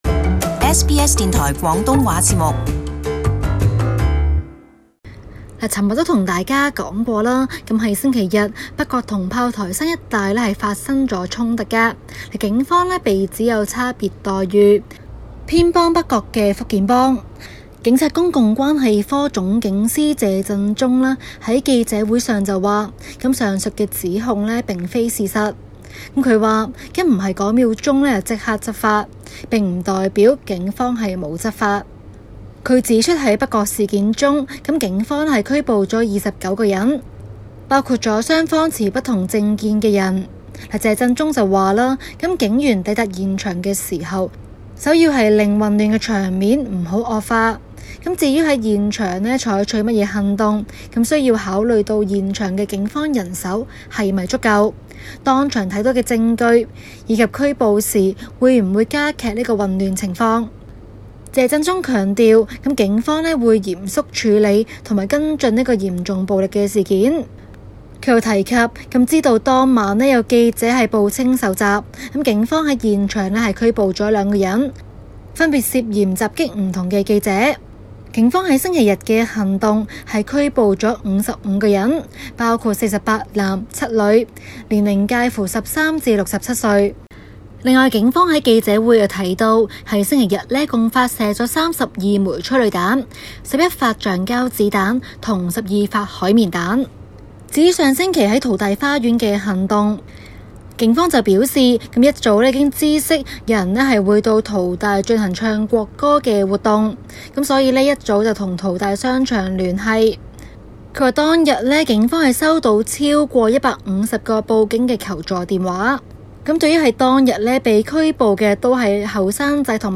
【時事報導】 陳淑莊於聯合國形容香港正陷「人道危機邊緣」